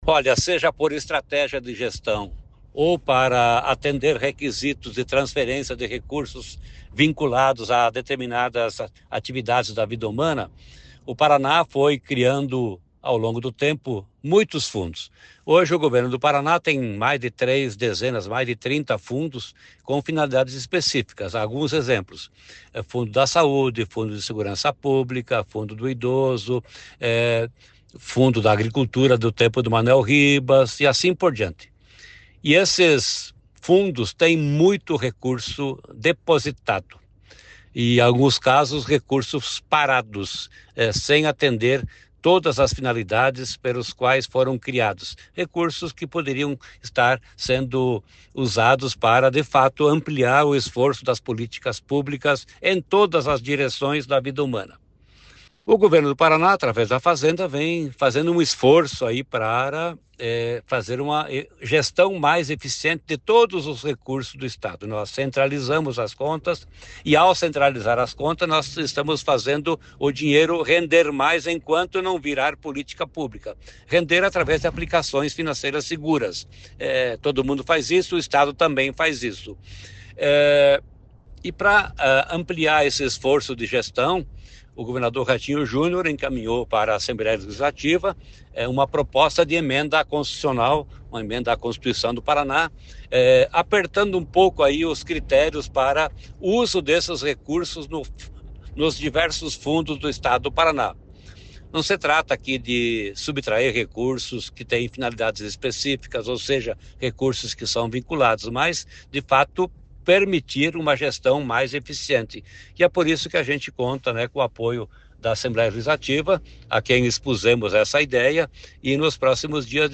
Sonora do secretário da Fazenda, Norberto Ortigara, sobre a PEC para centralizar saldos de fundos do Executivo no fim de cada ano